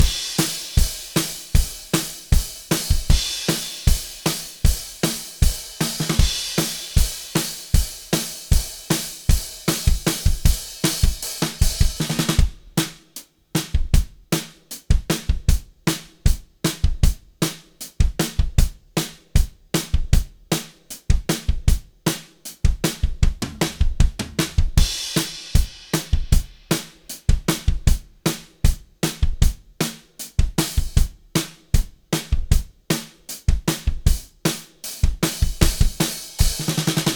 Hier mal zwei Mixe: Einmal auf PSI A21m und dann auf den neuen Monitoren.
Aber wenn man bedenkt, dass es anspruchsvolle Spuren sind mit Phasenproblemen und rund 14 Stück... ist es nicht verkehrt.